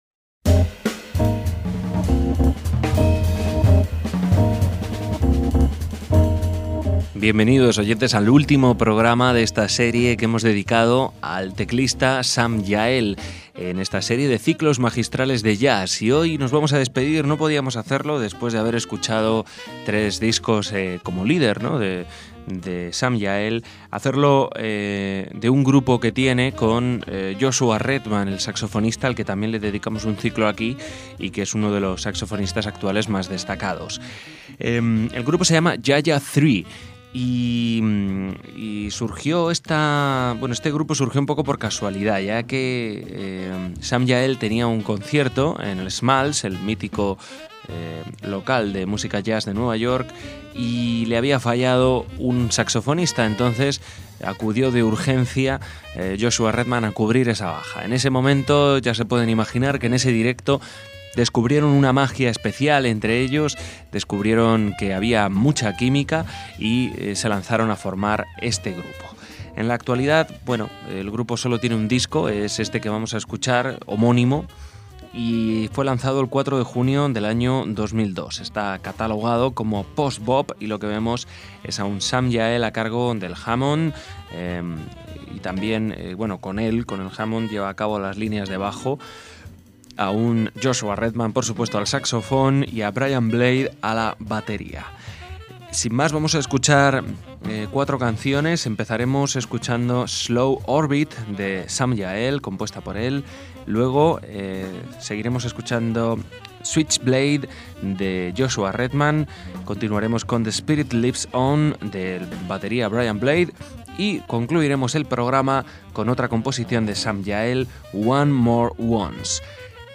tecladista
saxo
batería